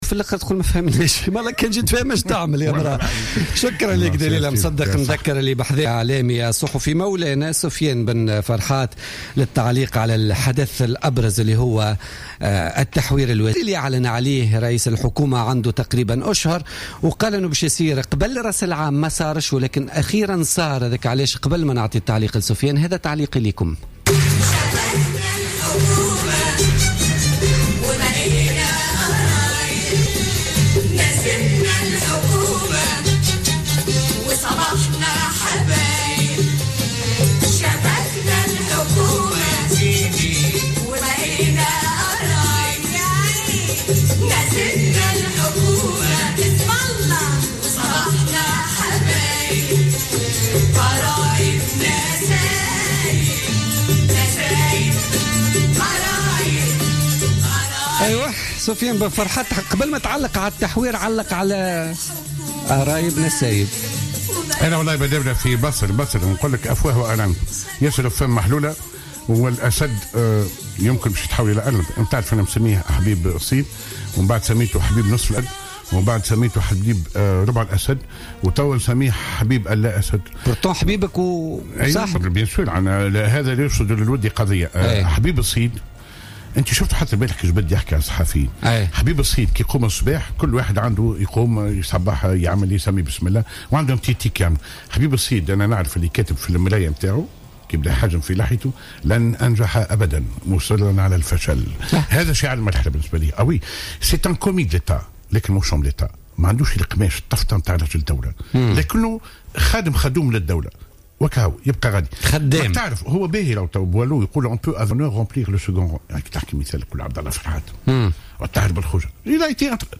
ضيف بوليتيكا